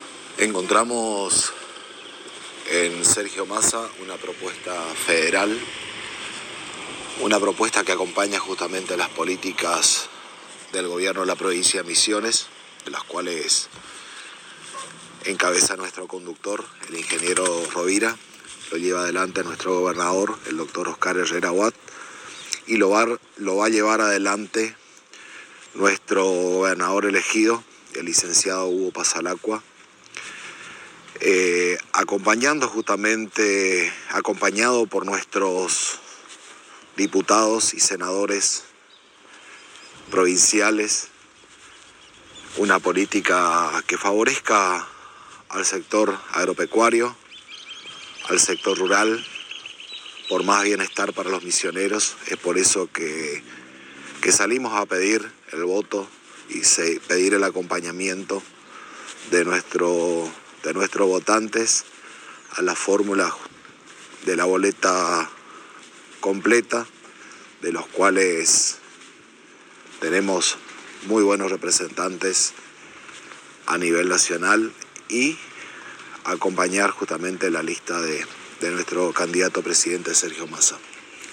El Intendente de Tres Capones, Ramón Gerega, en diálogo exclusivo con la ANG manifestó que Sergio Massa es el candidato a Presidente que está alineado con los intereses del Pueblo Misionero, y muestra de ello es el respaldo recibido por el conductor de la Renovación Carlos Rovira y toda la dirigencia renovadora.